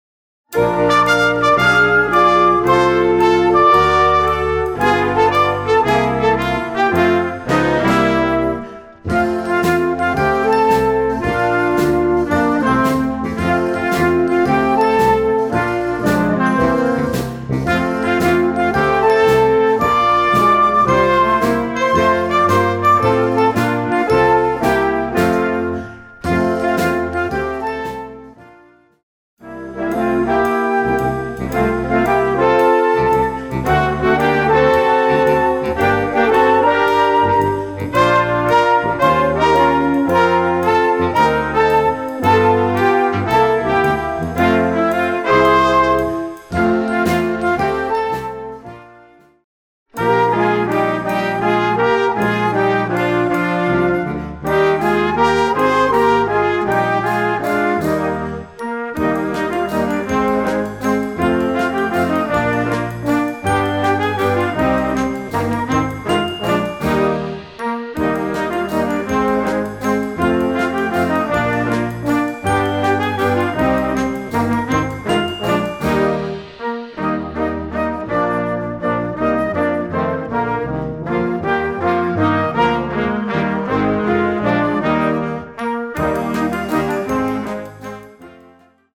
Gattung: Weihnachtsmusik für Jugendblasorchester
Besetzung: Blasorchester